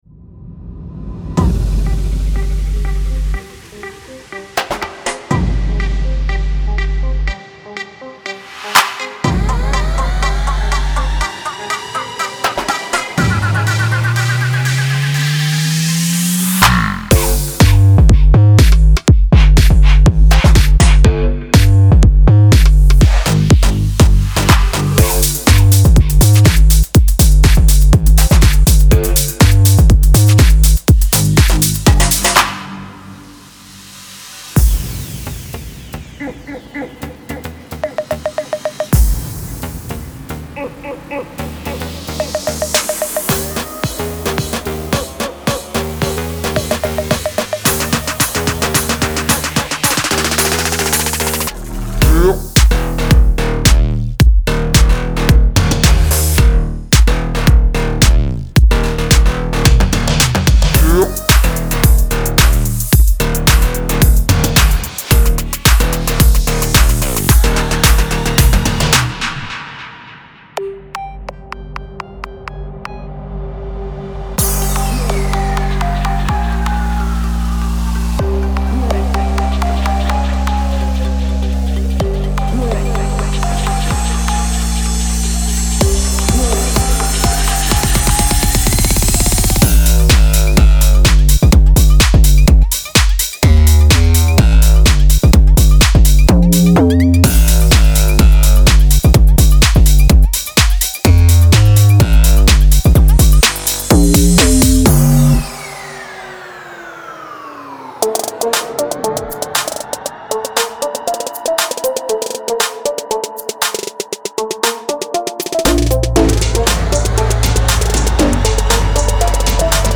Bass Collection
A powerful collection of 85 Serum presets featuring basses — from deep, to smooth, to downright filthy. Also, included are a custom selection of unique leads, designed to add that extra heat to your tracks.